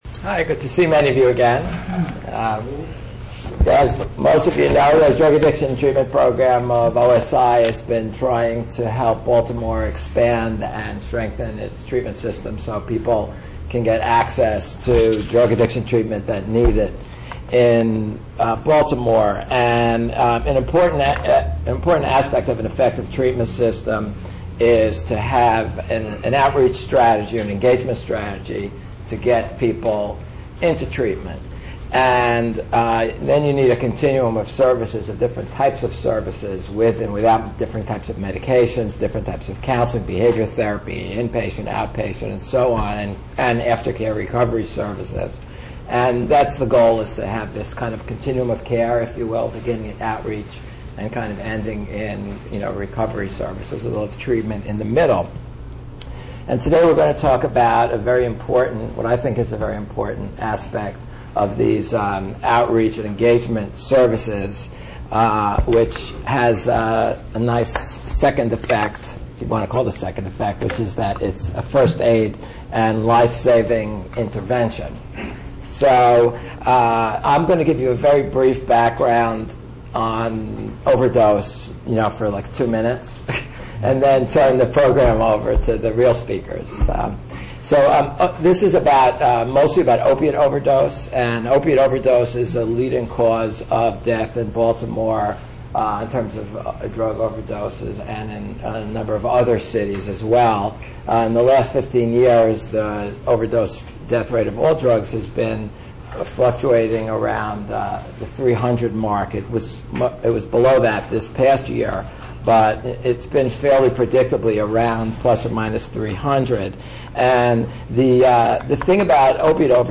Cosponsored by OSI-Baltimore's Drug Addiction Treatment Program, this forum discusses efforts to prevent and to treat drug overdoses.
During the forum, Staying Alive staff, prescribing physicians, participants and evaluators from the Johns Hopkins School of Public Health will describe the program and its progress in saving lives. The forum will also provide opportunity to discuss the implementation of the program, ways to bring it to a larger scale, and how the program fits within the city’s continuum of treatment services.